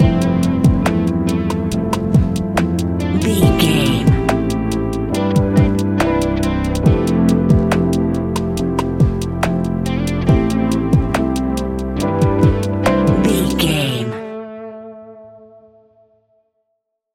Ionian/Major
A♭
chilled
laid back
Lounge
sparse
new age
chilled electronica
ambient
atmospheric
morphing
instrumentals